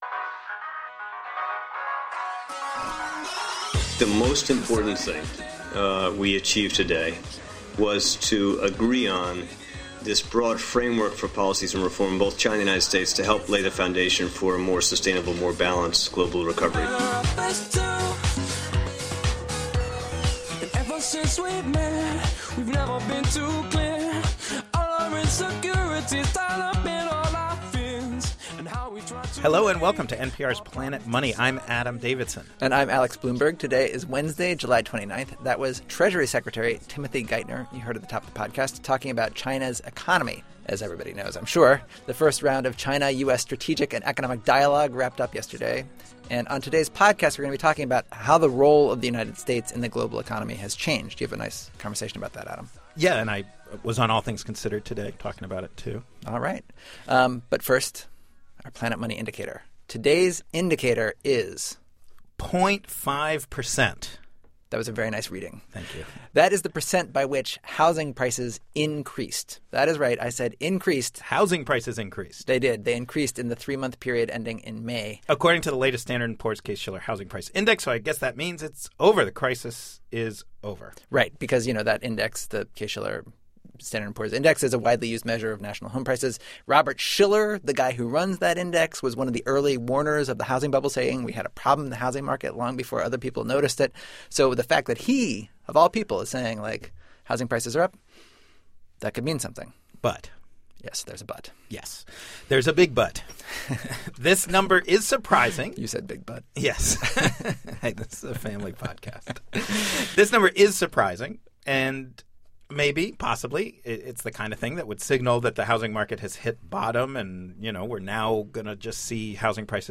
Almost a year into the financial crisis, we ask foreign policy analyst Ian Bremmer to take stock of America's position in the world. Bremmer, author of The Fat Tail and president of the risk consulting firm Eurasia Group, says America has lost some of its power.